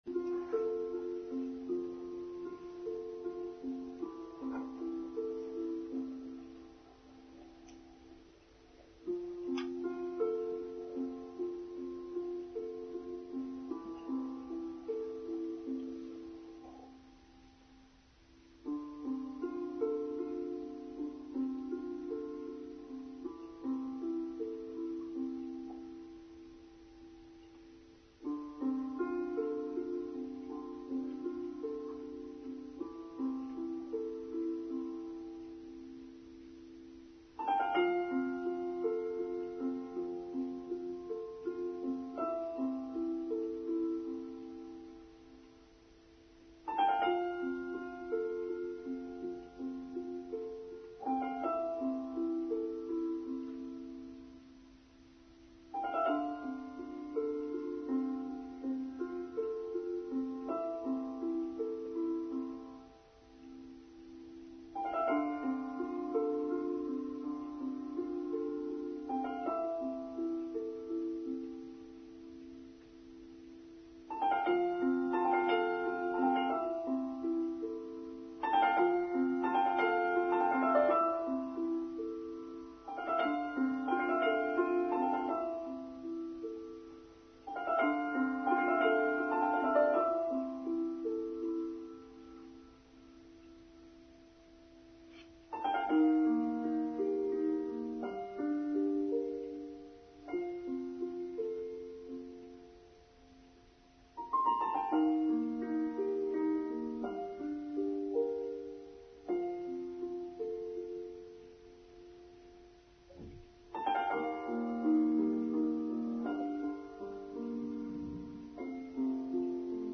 Love: the Greatest Power: Online Service for Sunday 12th May 2024